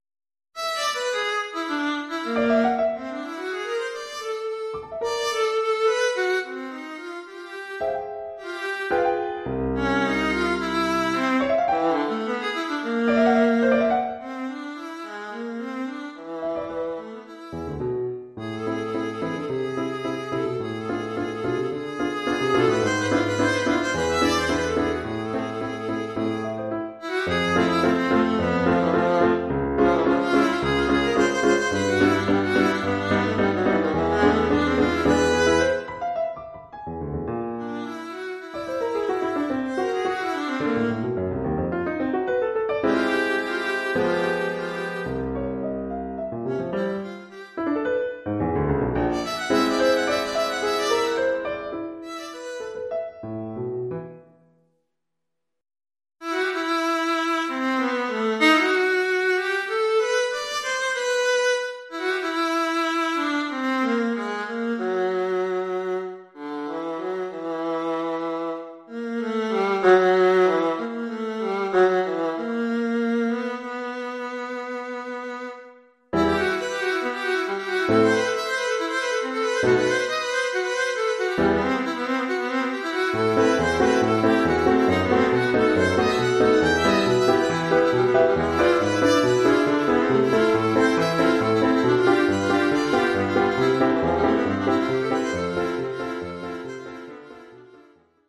Formule instrumentale : Alto et piano
Oeuvre pour alto avec
accompagnement de piano.